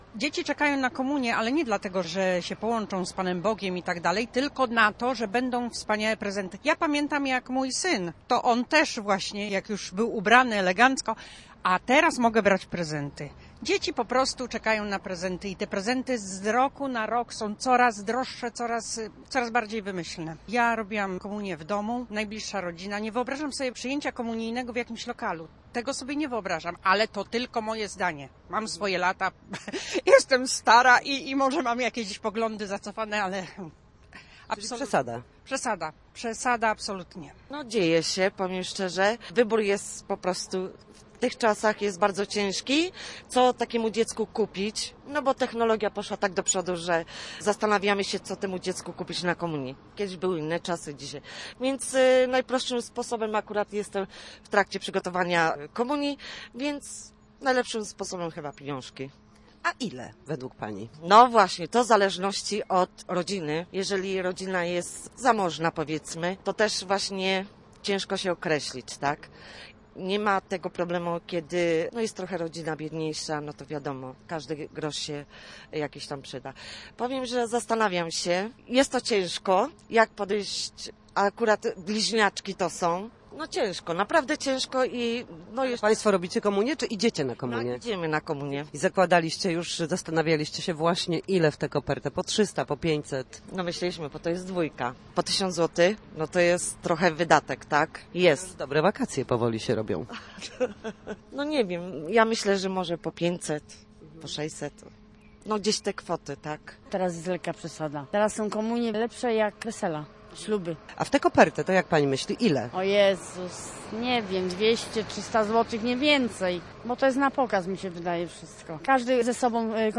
Zapytaliśmy mieszkańców Słupska o to, co sądzą na temat podarunków komunijnych, także o to, czy dzieci do pierwszej komunii świętej idą z przekonania i wiary, czy dla prezentów właśnie, posłuchaj: https